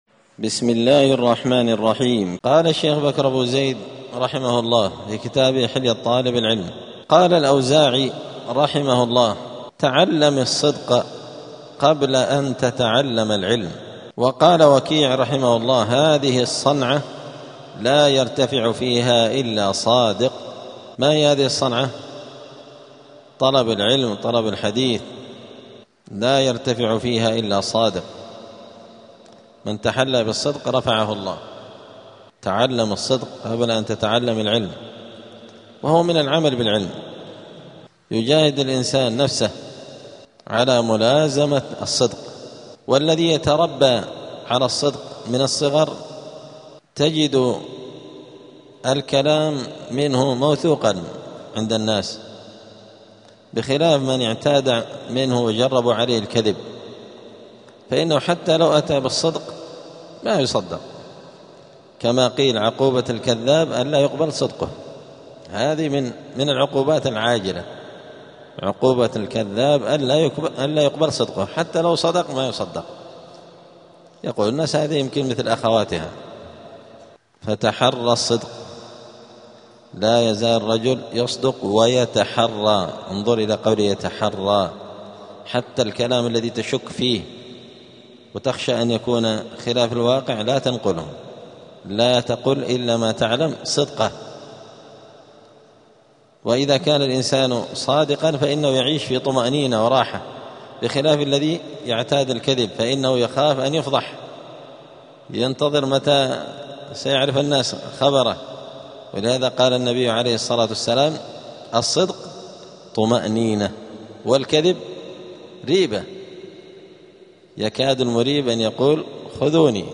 الأربعاء 2 شعبان 1447 هــــ | الدروس، حلية طالب العلم، دروس الآداب | شارك بتعليقك | 5 المشاهدات